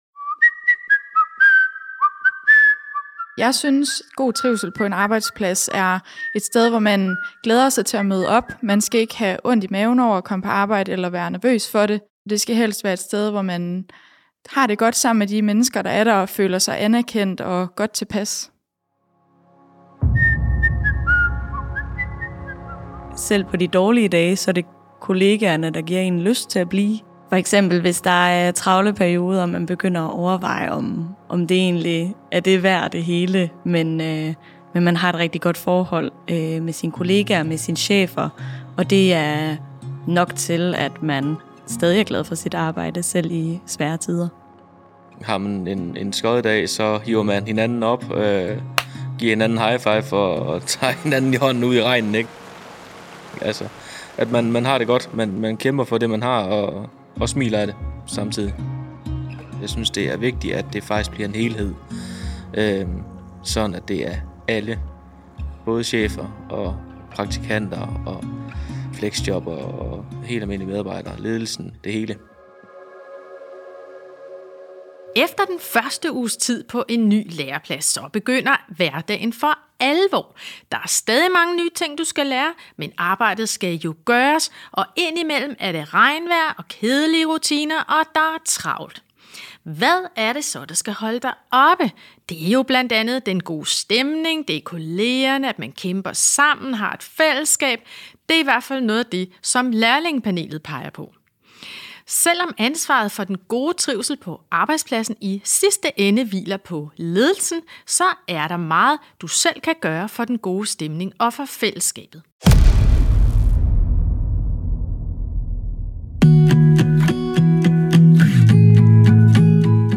I denne episode får du erfaringer og gode råd om trivsel og fællesskab fra lærlingepanelet, og vi besøger en virksomhed for at høre, hvordan ledelsen arbejder med trivsel på arbejdspladsen.